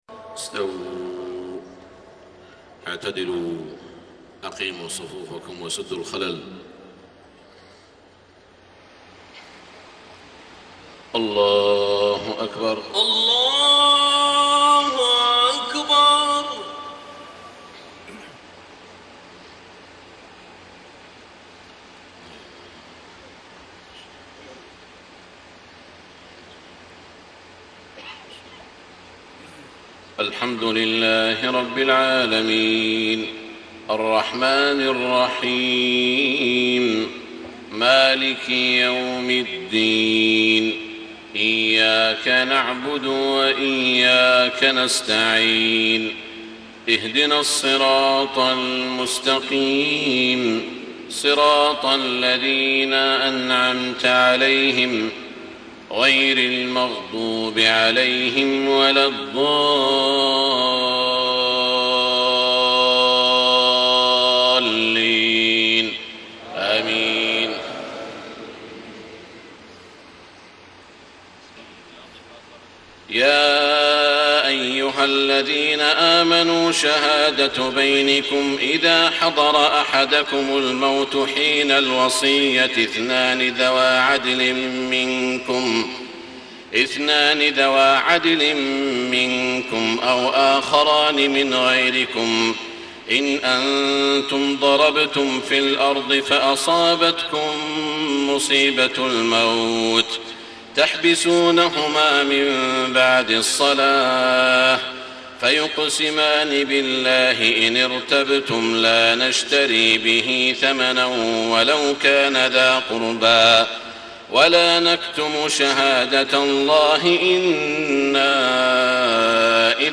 صلاة العشاء 2-8-1434 من سورة المائدة > 1434 🕋 > الفروض - تلاوات الحرمين